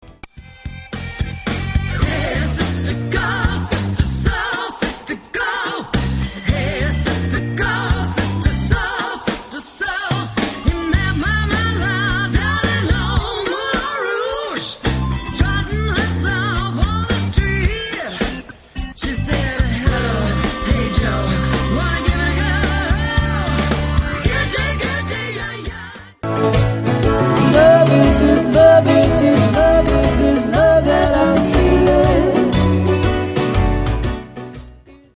comprises of Guitar Vocals + Female Vocals